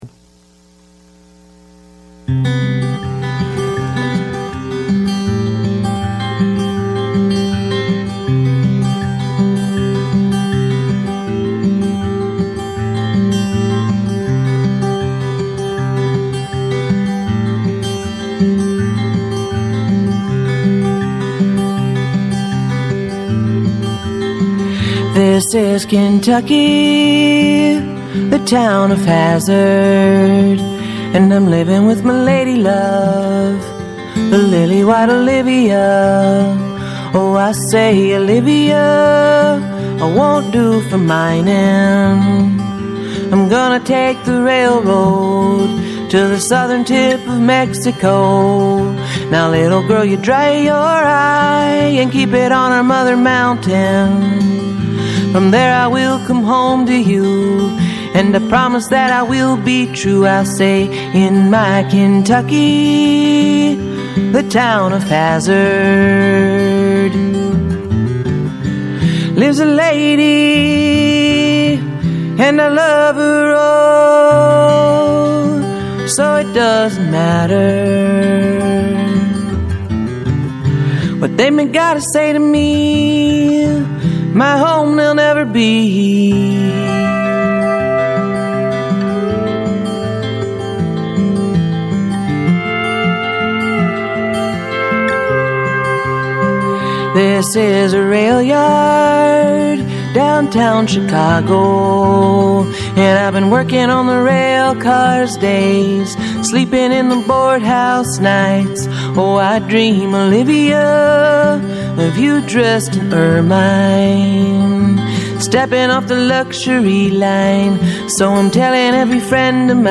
radio show with musical guest